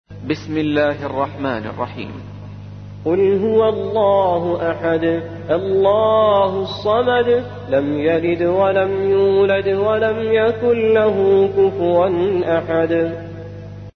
112. سورة الإخلاص / القارئ